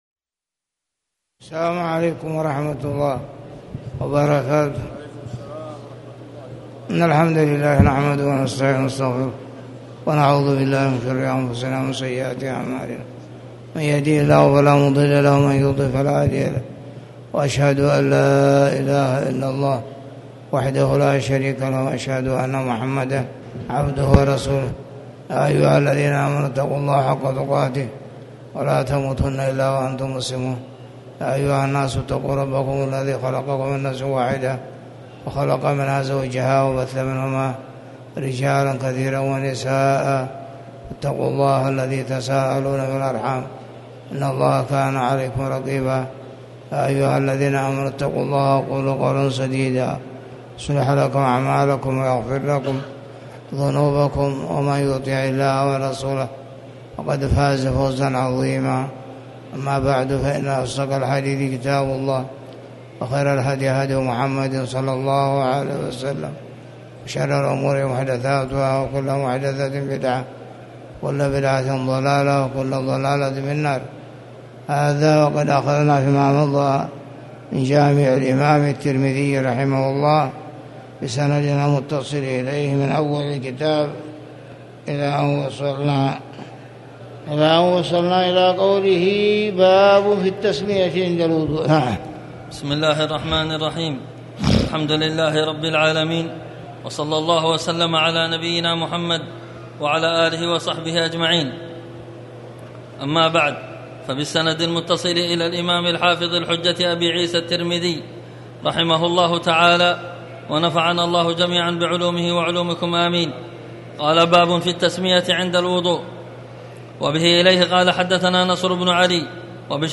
تاريخ النشر ٢٠ محرم ١٤٤٠ هـ المكان: المسجد الحرام الشيخ